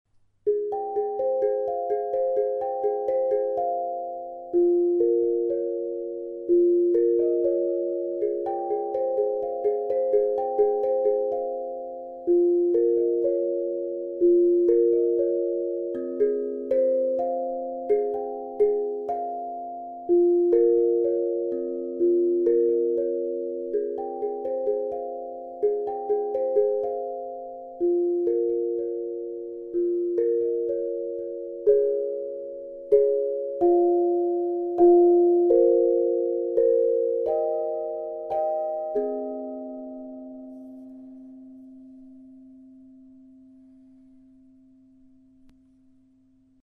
Tongue Drum 9 Noten – Harmony von Zenko
Der Klang des Zenko ist von seltener Weichheit geprägt und breitet sich wie eine sanfte Welle der Harmonie aus.
Sie umfasst die C-Moll und C#-Moll Tonleiter. Im Zentrum dieser Tonfolge liegt das C4 als stützender Basston.
⁃ 9 Noten : C4 F4 Ab4 Bb4 C5 C#5 Eb5 F5 G5
STEEL-TONGUE-DRUM-ZENKO-9-NOTEN-–-HARMONY.mp3